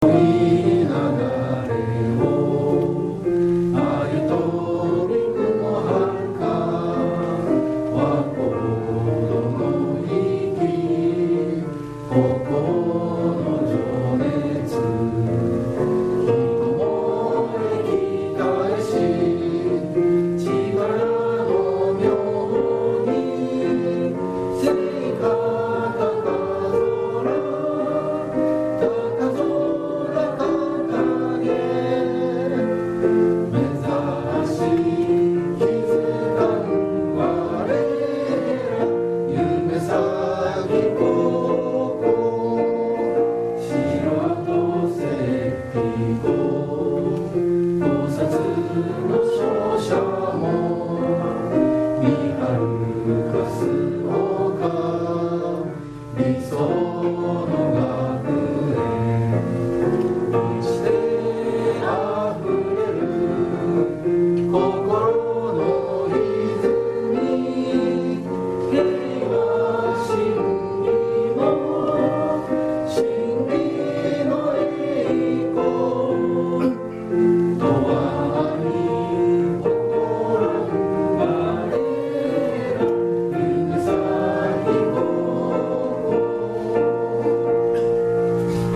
４月８日　着任式、始業式
校歌斉唱の音声（ 2番、 3番）です。
校歌斉唱（２番３番）.MP3